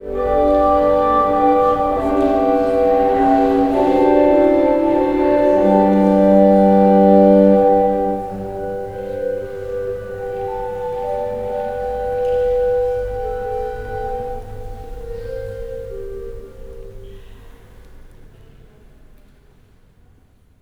IN CHURCH1-R.wav